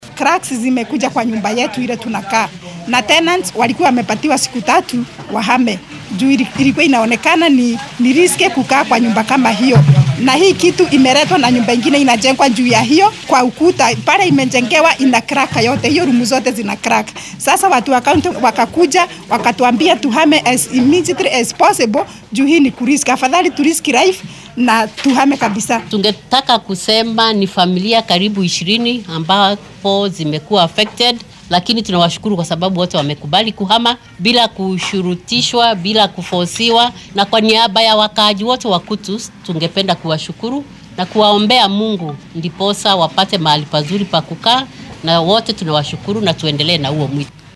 Qaar ka mid ah qoysaska halkaasi laga saaray ayaa warbaahinta la hadlay.